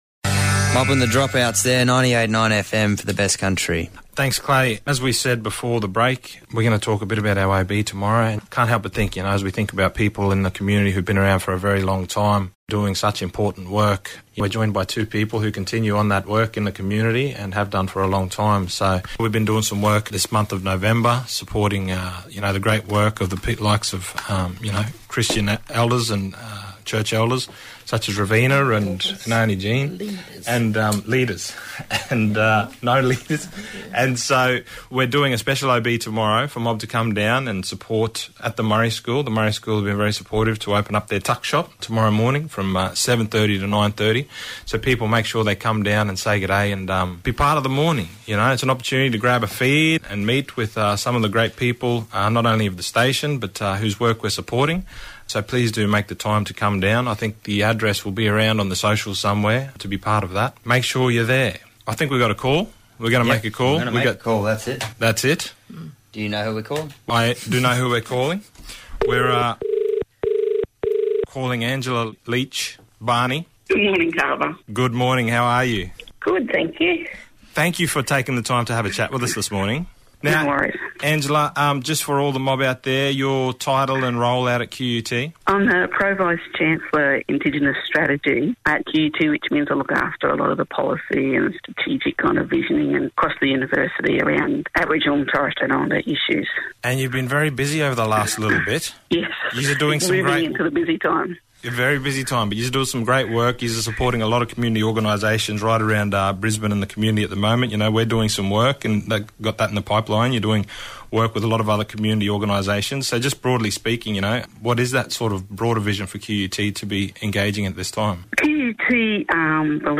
At the Murri School on Friday 29th November, 2019.